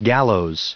Prononciation du mot gallows en anglais (fichier audio)
Prononciation du mot : gallows